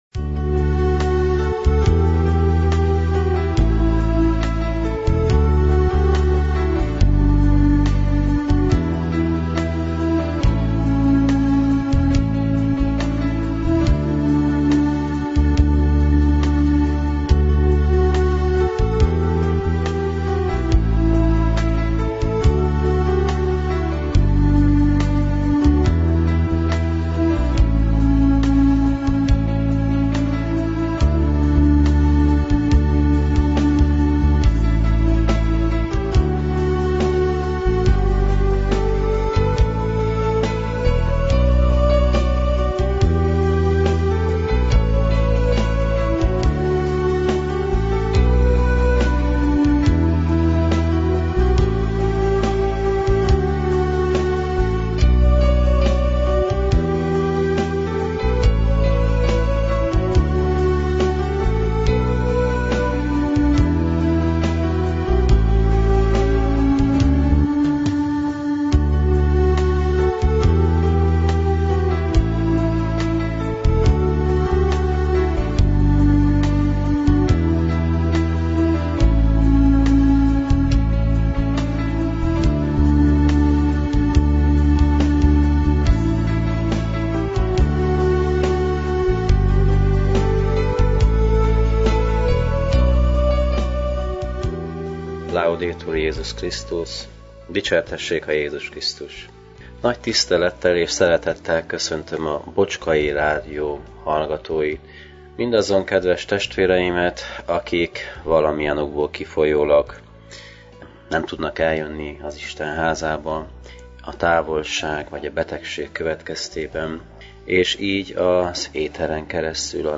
a clevelandi Szent Imre Katolikus Templomból.